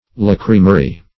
Search Result for " lacrymary" : The Collaborative International Dictionary of English v.0.48: Lacrymary \Lac"ry*ma*ry\, Lacrytory \Lac"ry*to*ry\, Lacrymose \Lac"ry*mose\ See Lachrymary , Lachrymatory , Lachrymose .